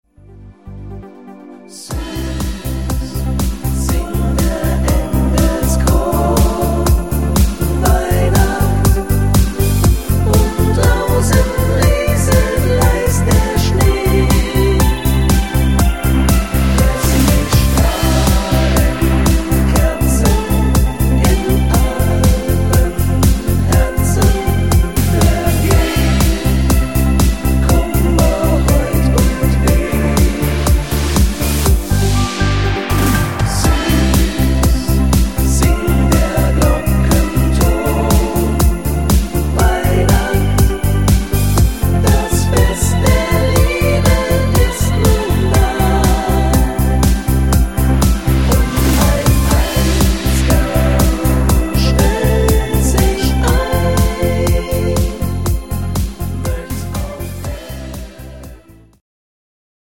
im Schlagersound